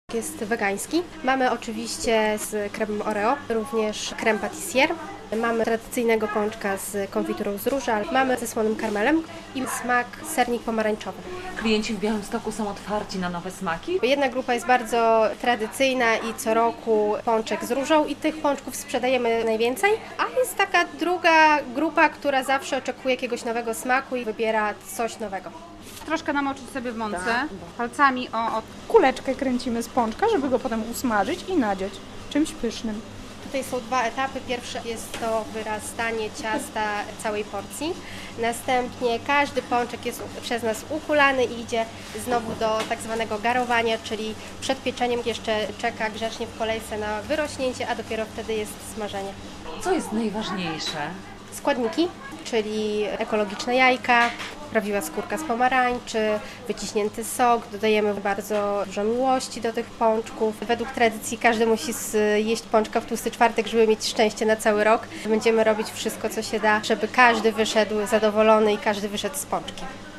Pracowita noc w piekarniach przed Tłustym Czwartkiem - relacja